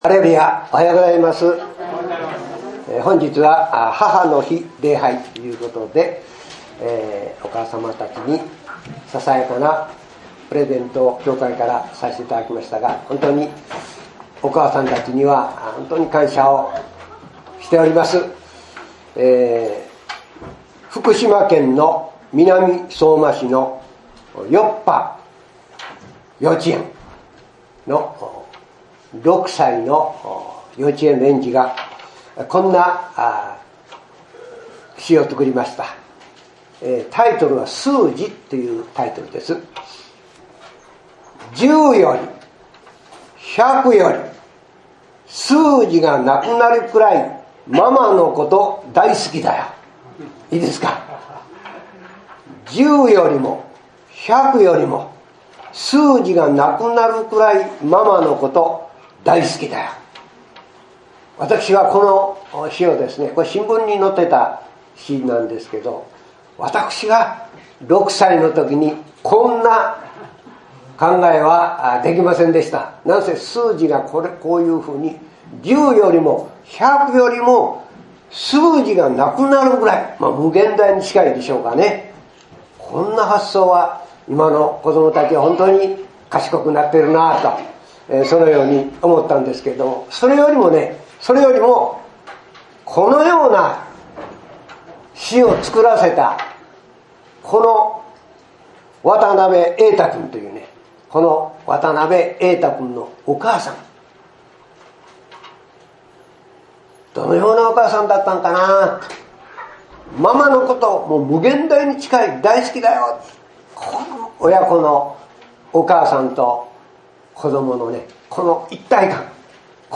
イエスの大胆な主張 | 桑名キリスト教会